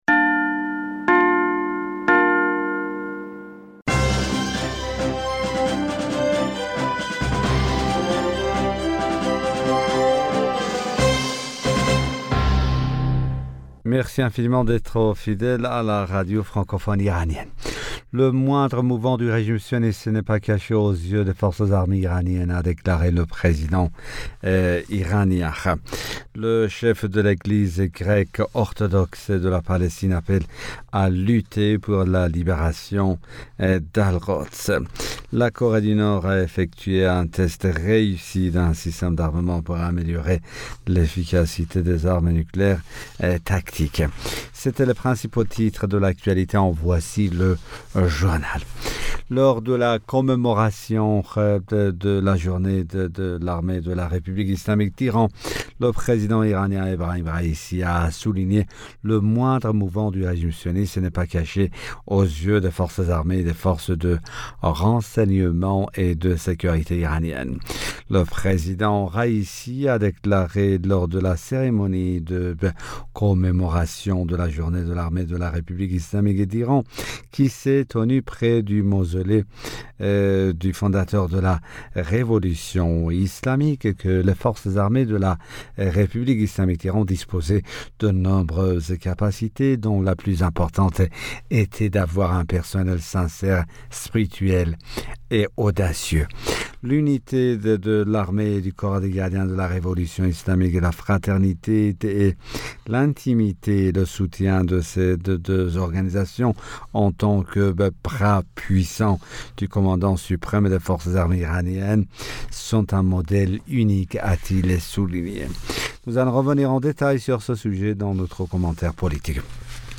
Bulletin d'information Du 19 Avril 2022